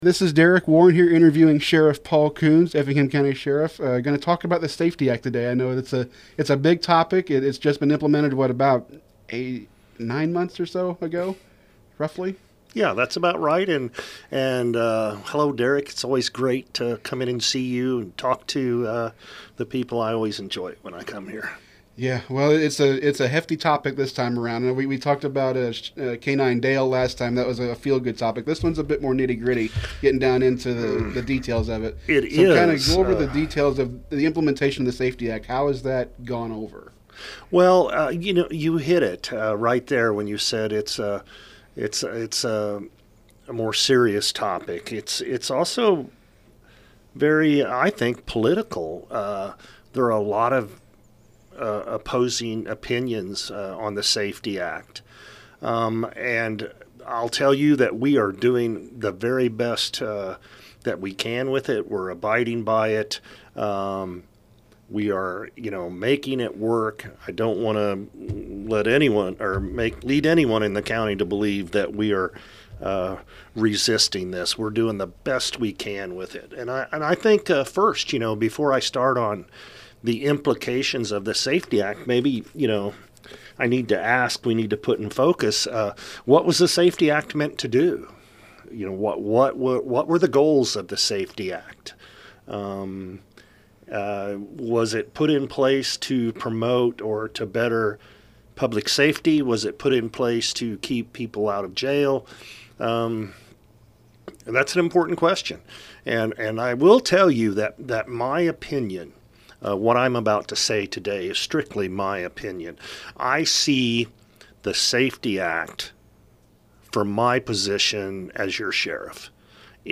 Effingham County Sheriff Paul Kuhns Shares Thoughts On Implementation Of SAFE-T Act In Interview
sheriff-kuhns-interview-about-safe-t-act-6-18-24.mp3